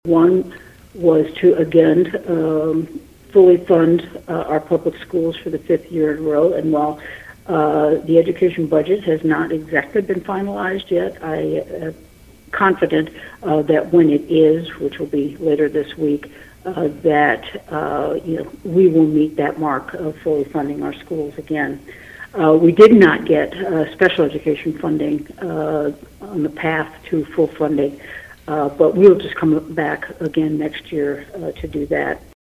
Kansas Governor Laura Kelly had a lot of battles with legislative Republicans during the 2023 session, but she said there were a lot of positive developments as well during an interview airing on KVOE’s Newsmaker segment Thursday.